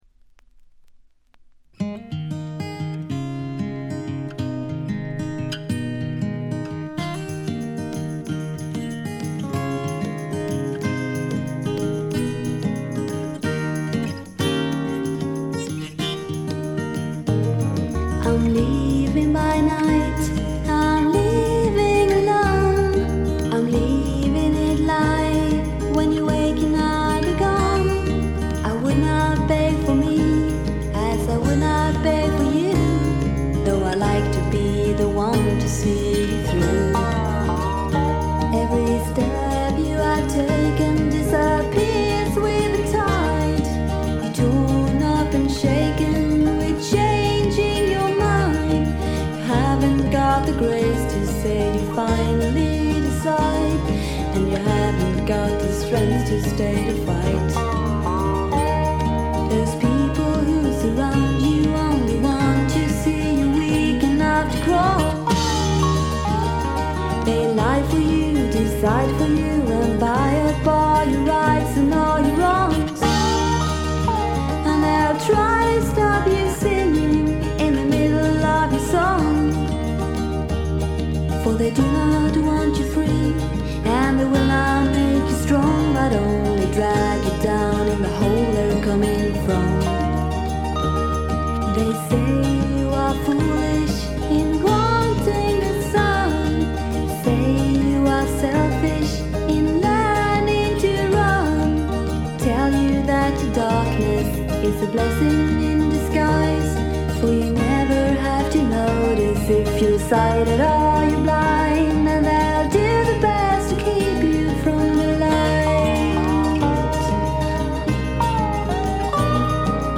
静音部での軽いチリプチ程度。
ちょっと舌足らずなクリスタルな甘えん坊ヴォイスが実に魅力的でノックアウト必至。
試聴曲は現品からの取り込み音源です。